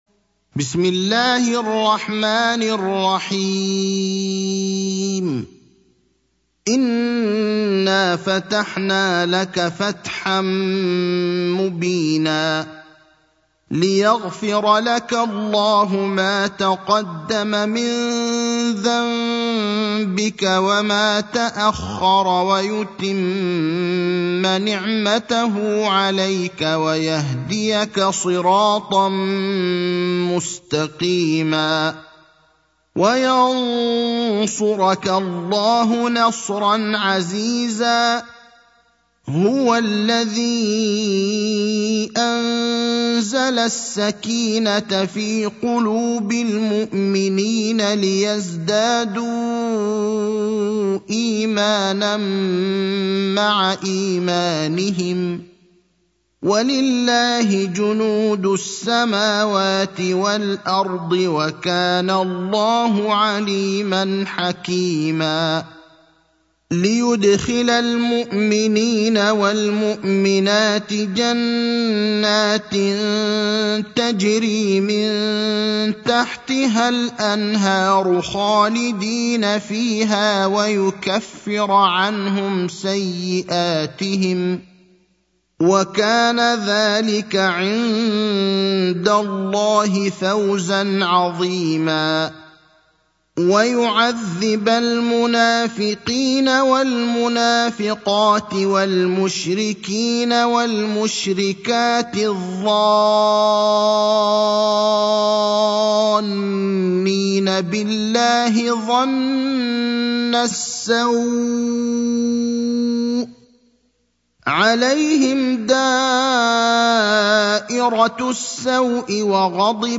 المكان: المسجد النبوي الشيخ: فضيلة الشيخ إبراهيم الأخضر فضيلة الشيخ إبراهيم الأخضر سورة الفتح